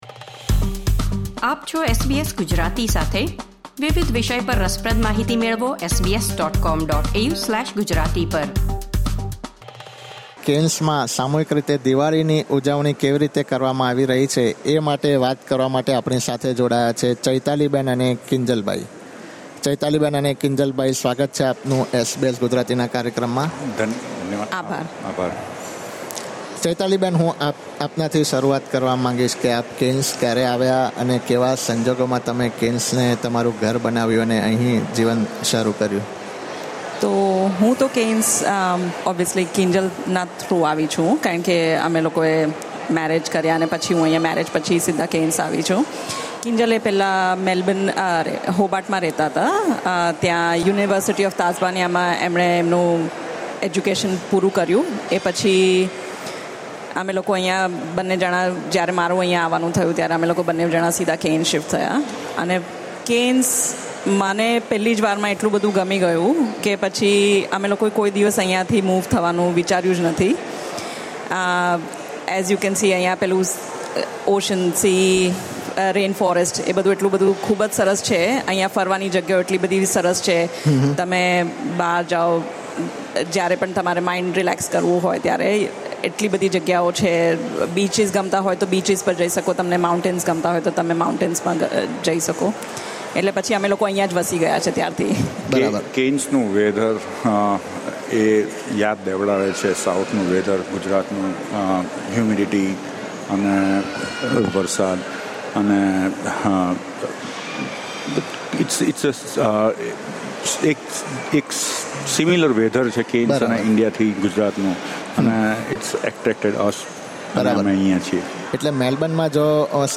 special outdoor broadcast at Cairns Central Shopping Centre